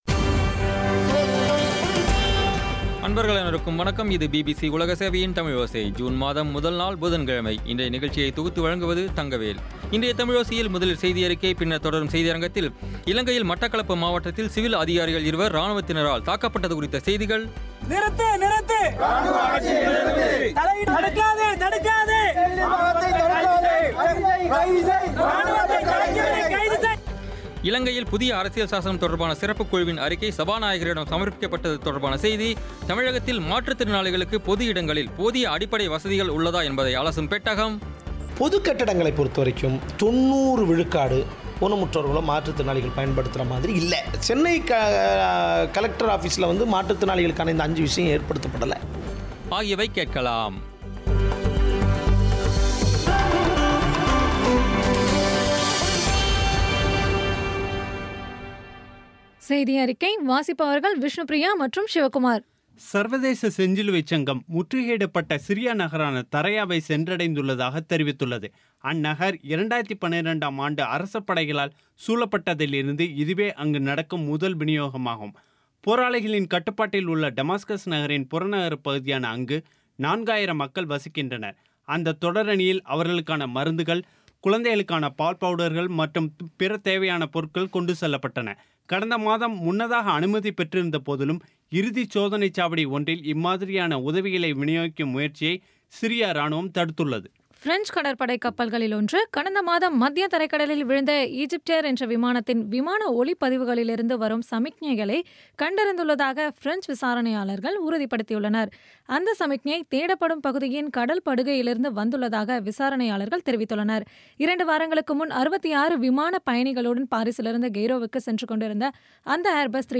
இன்றைய தமிழோசையில், முதலில் செய்தியறிக்கை, பின்னர் தொடரும் செய்தியரங்கத்தில்,